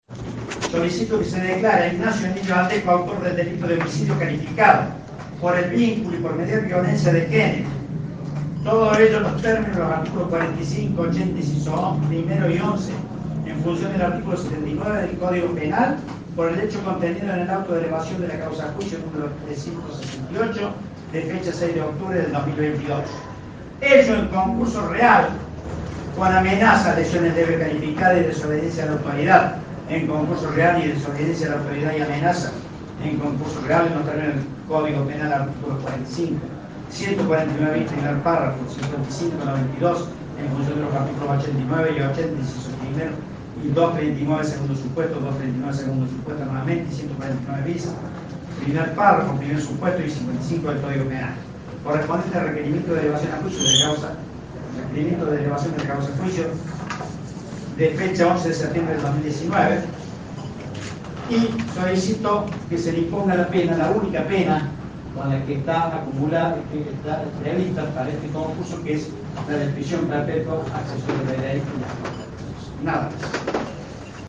AUDIO – FISCAL FRANCISCO MÁRQUEZ
La-palabra-del-Fiscal-de-Camara-Francisco-Marquez.mp3